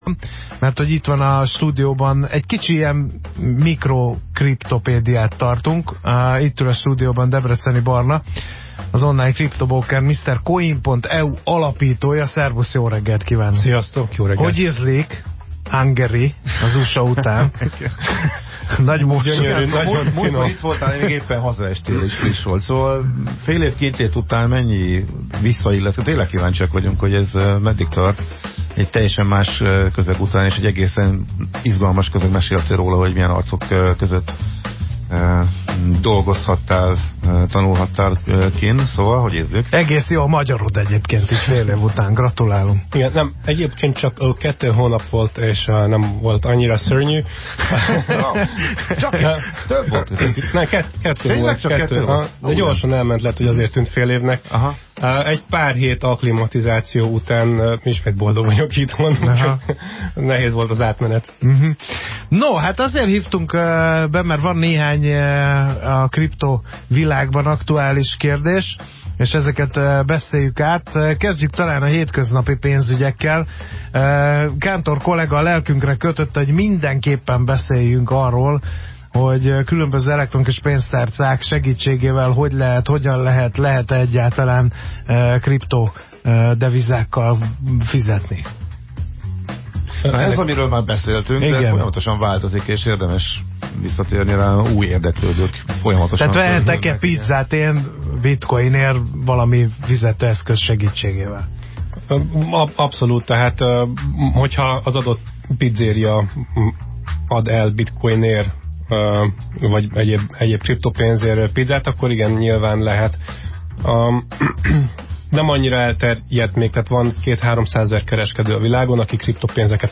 A kriptopénz a téma a pénteki Millásreggeliben. Érdekes témák, neves személyek, színvonalas beszélgetések.